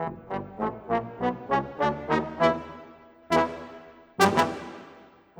Rock-Pop 20 Trombone _ Tuba 03.wav